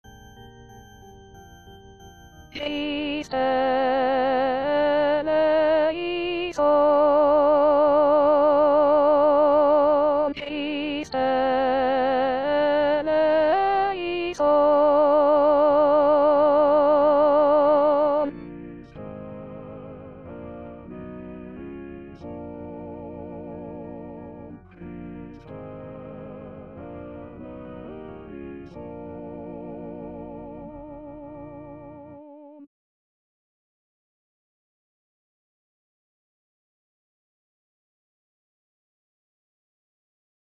Chanté:     S1   S2